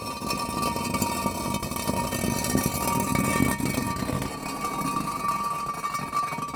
StoneDrag.ogg